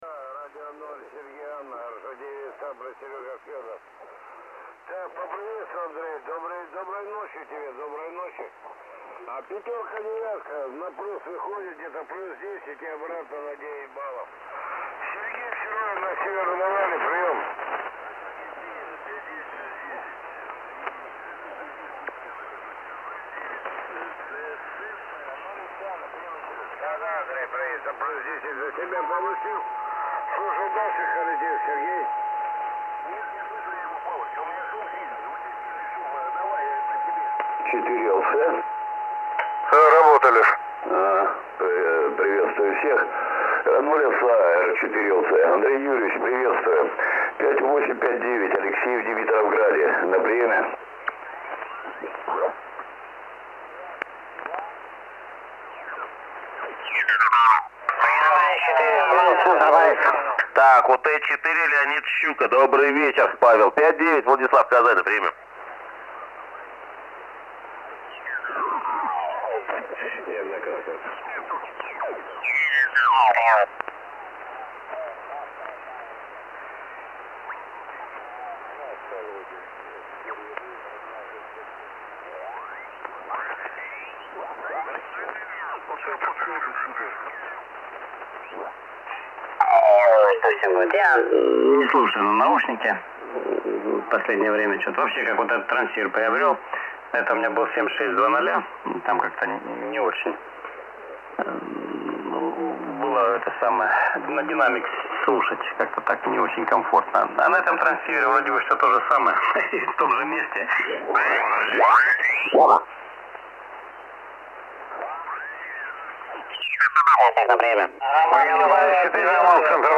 При максимальной громкости в 100% явных искажений не слышно.
Вот запись эфира на 40 метрах: